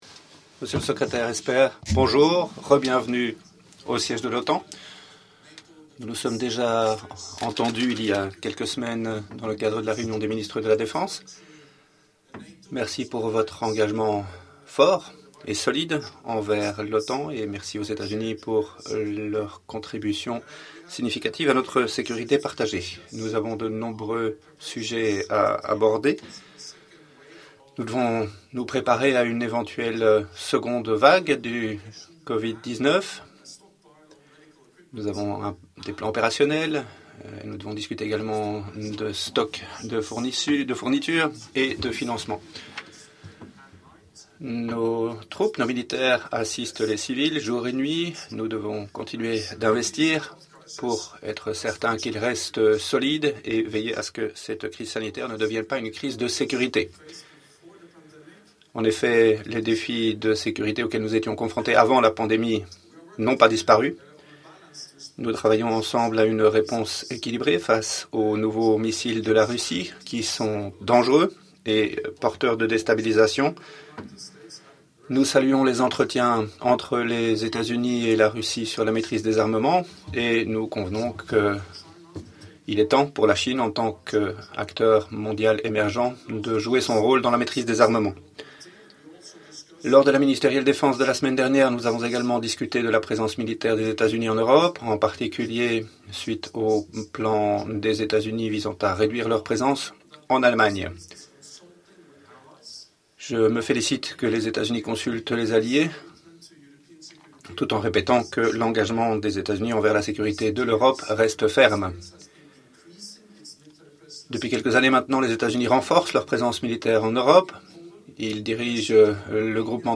Statements by the NATO Secretary General Jens Stoltenberg and the US Secretary of Defense, Mark Esper